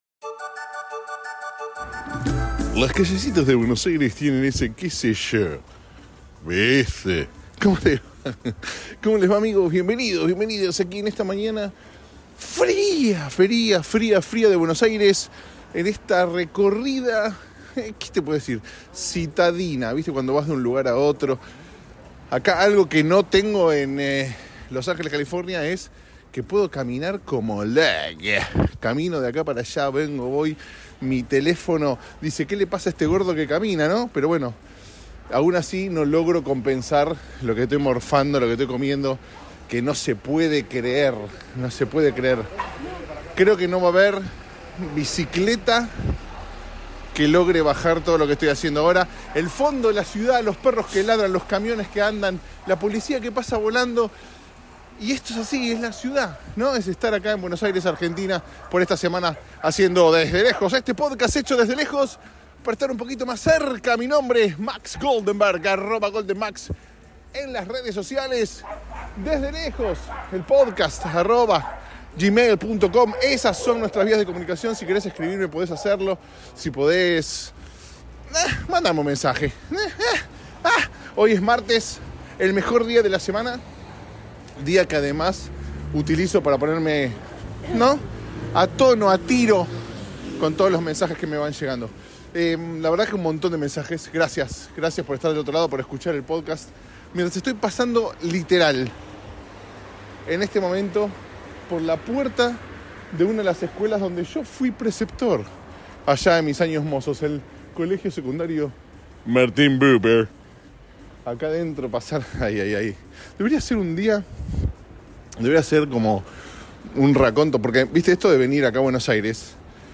Mientras camino por la ciudad, se va grabando este episodio.